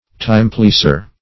Search Result for " timepleaser" : The Collaborative International Dictionary of English v.0.48: Timepleaser \Time"pleas`er\, n. One who complies with prevailing opinions, whatever they may be; a timeserver.